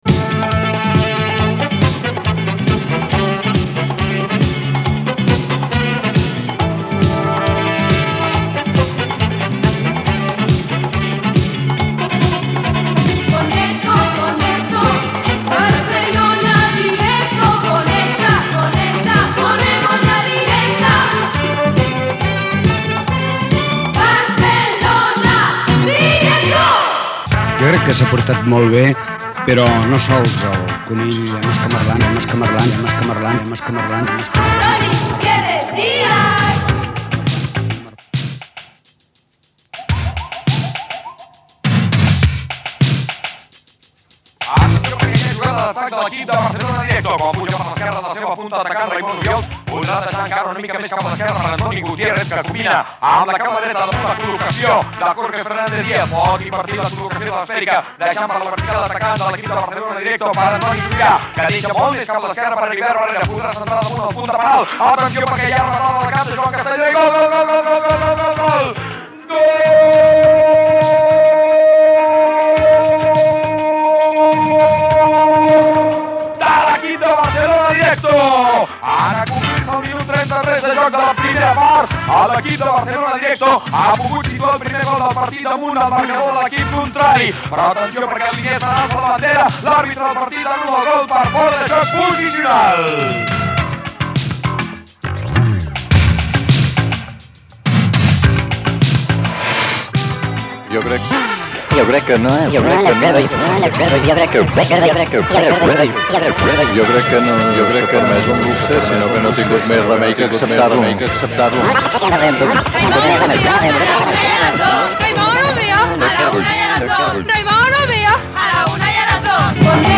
Muntatge de diverses promocions del programa.